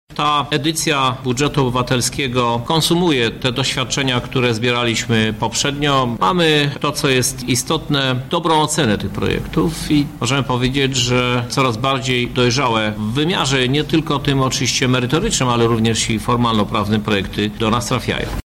• mówi prezydent Lublina, Krzysztof Żuk